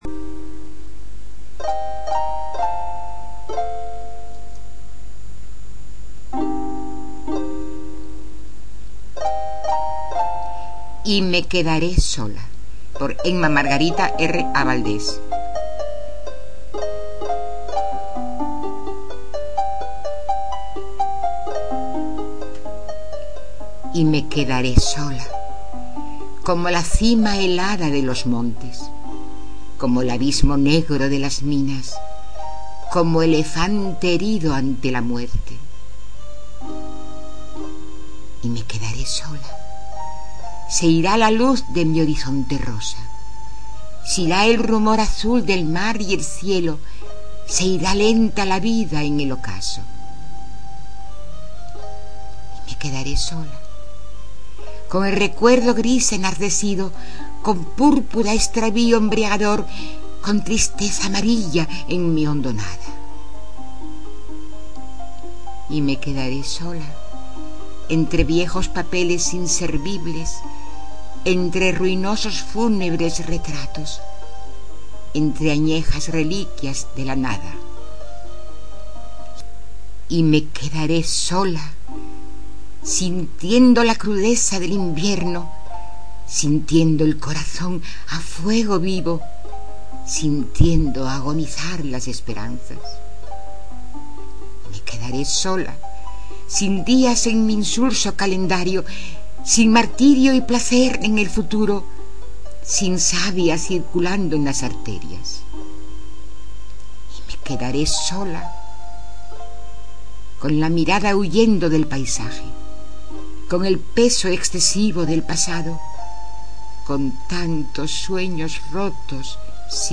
En mp3, recitada por la autora.